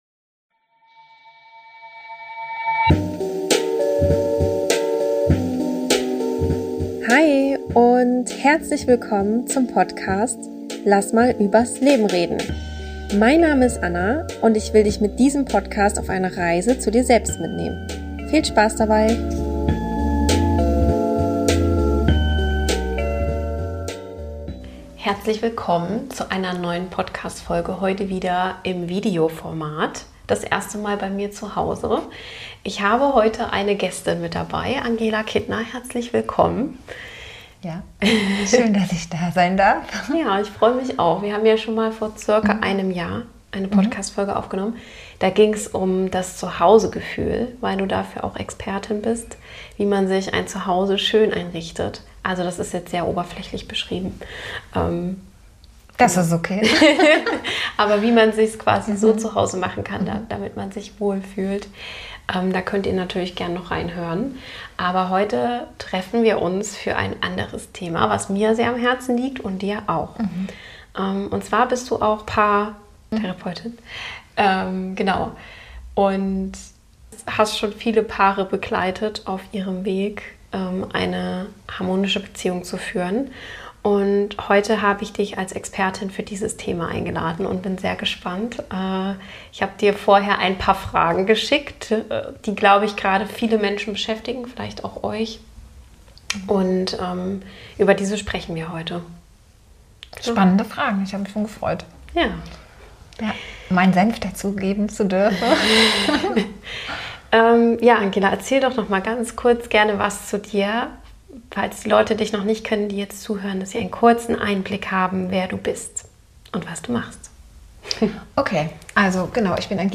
Im Interview mit Paartherapeutin